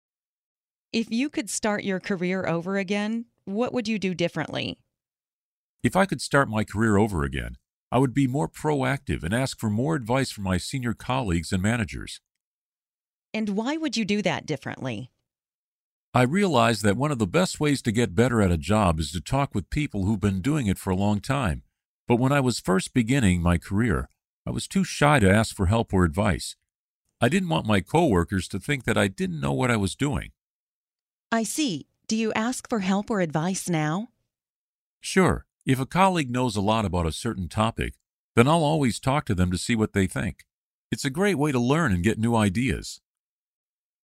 Learn different ways to answer the interview question 'If you could start your career over again, what would you do differently?', listen to an example conversation, and study example sentences.